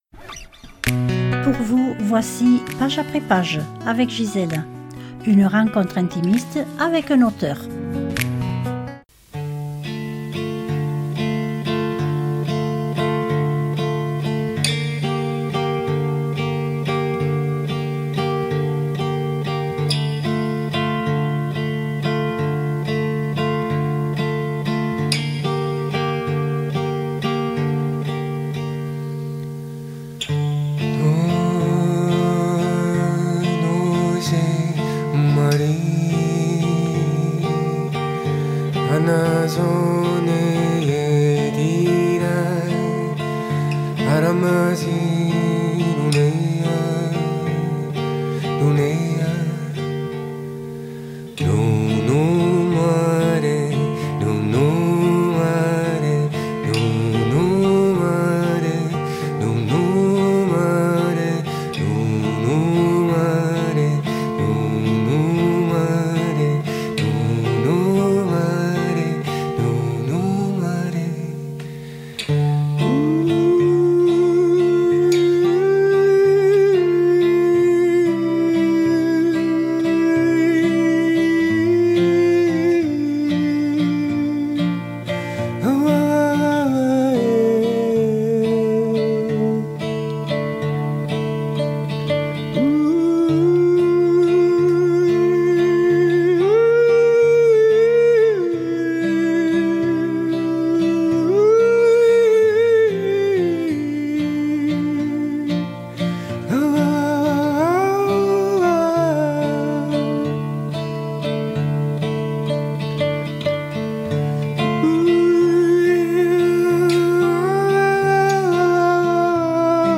Une rencontre littéraire intimiste avec un auteur.